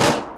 High For This Snare.wav